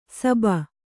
♪ saba